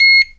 beep_01.wav